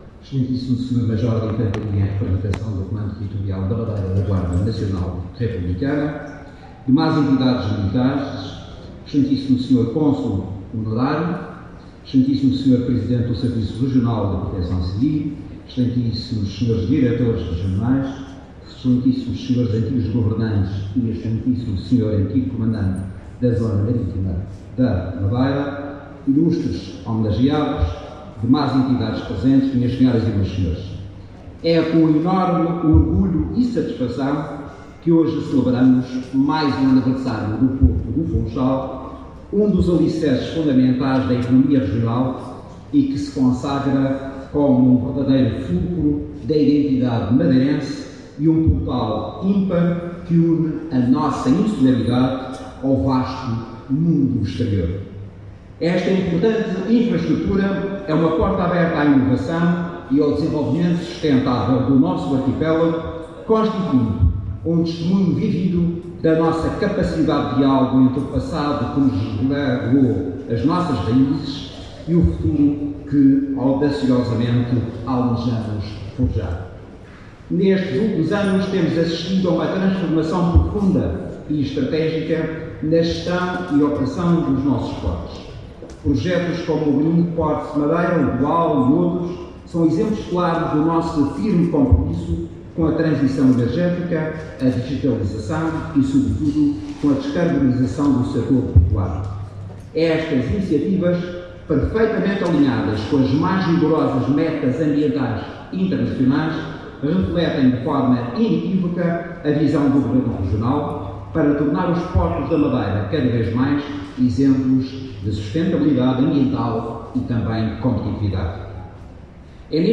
José Manuel Rodrigues_Dia Porto Funchal 2025.mp3